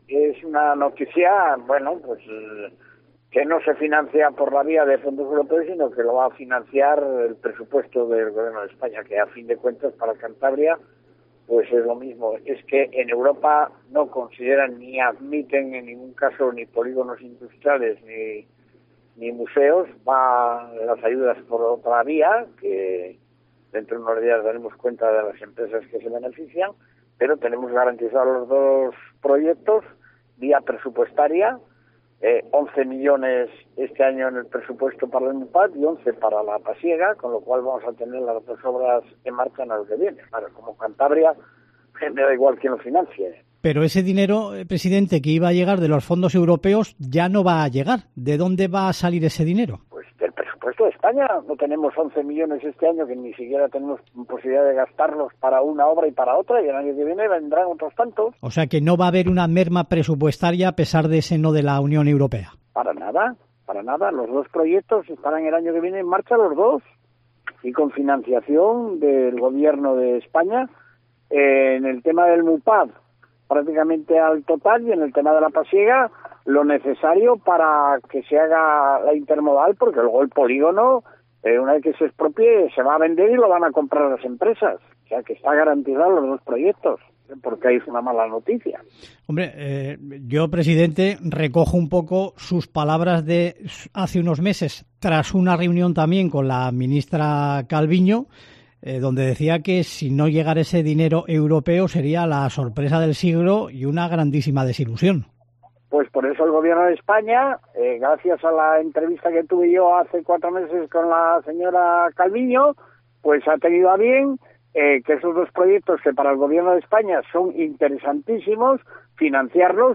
Entrevista a Miguel Ángel Revilla